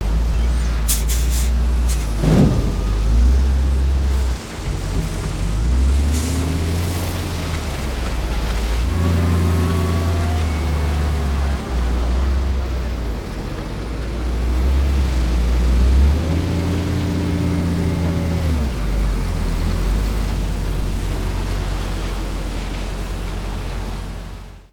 Maniobras de un camión